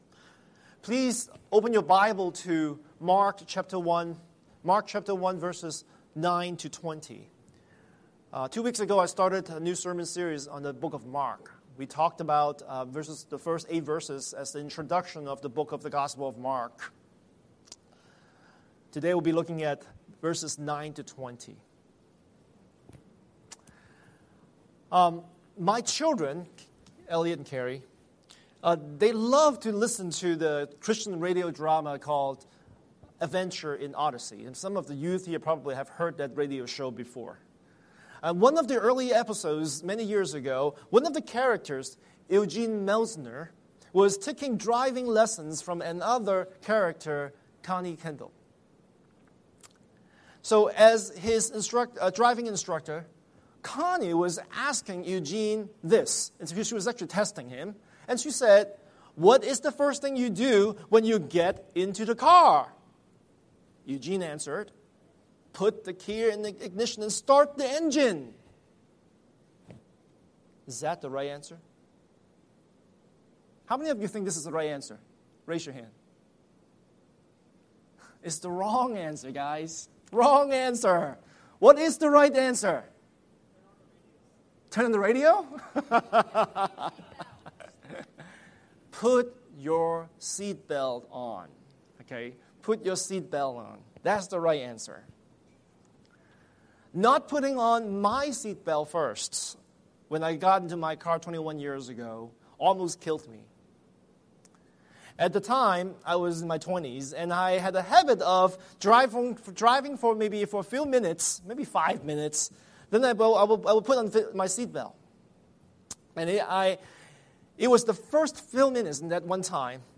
Scripture: Mark 1:9-20 Series: Sunday Sermon